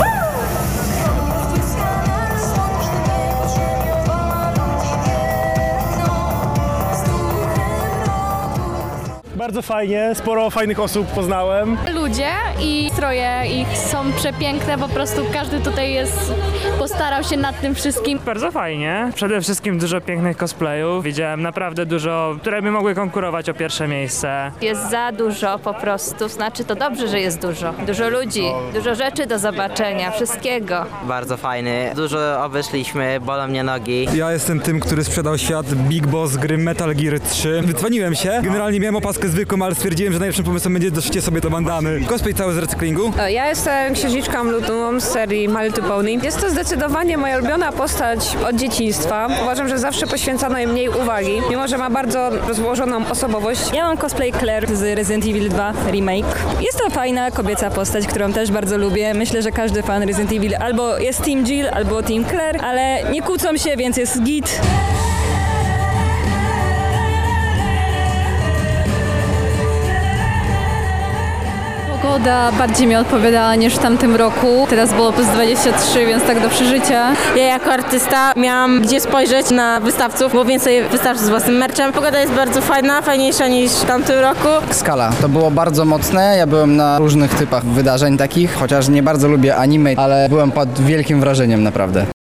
Relacja Pyrkon 2025